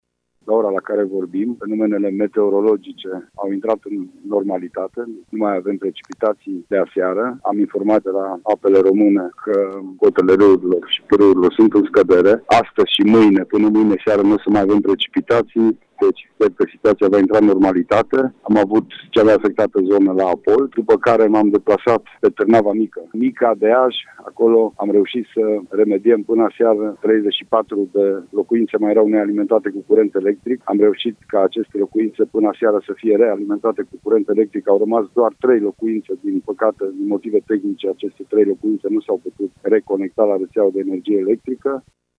În acest moment cotele râurilor din judeţ au scăzut şi situaţia a intrat în normalitate spune Prefectul județului Mureș, Lucian Goga: